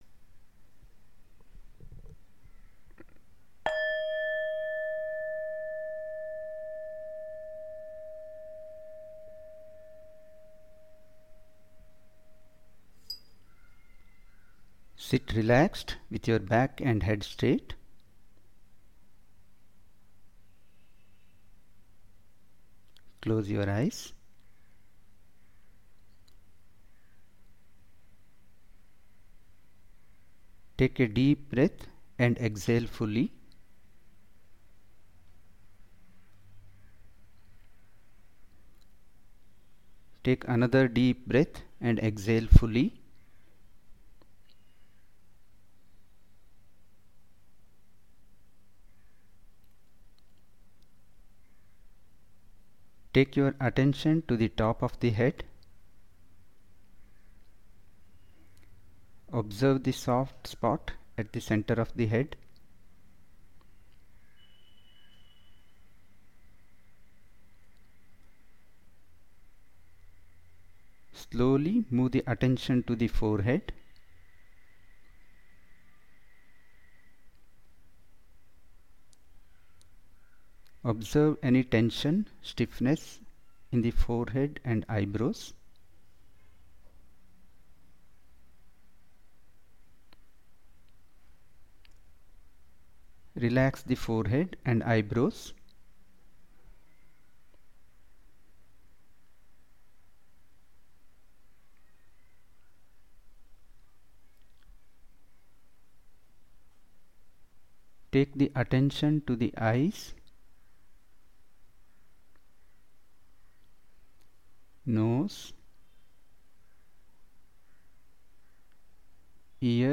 Audio Instructions